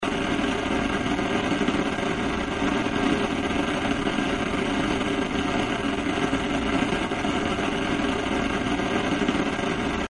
Звуки шипения телевизора
На этой странице собраны разнообразные звуки шипения телевизора: от едва заметного фонового шума до интенсивных помех.